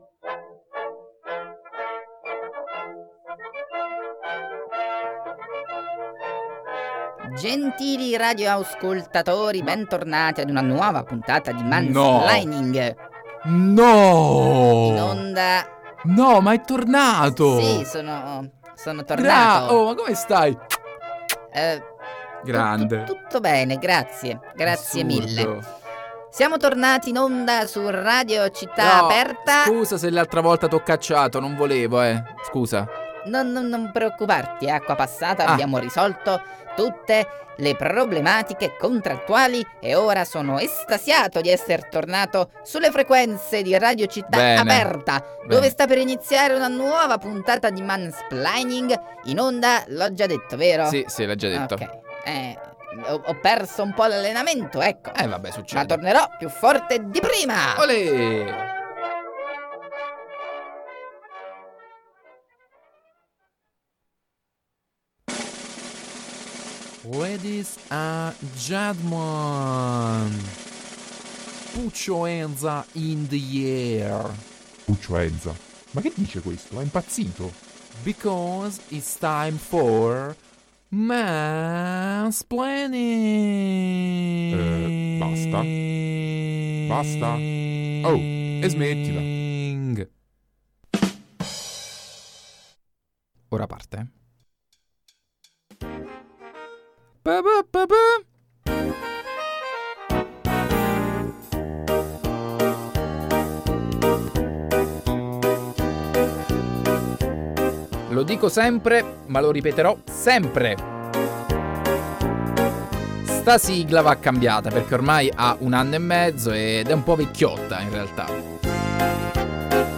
…anzi, fuori dagli studi, perché l’episodio del 20 giugno è stato trasmesso in ESTERNA da un famoso luogo di Roma.